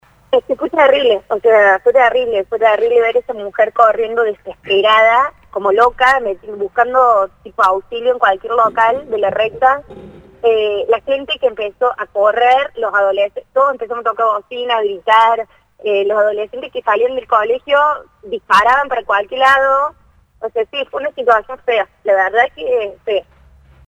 se emocionó al expresar su miedo y angustia.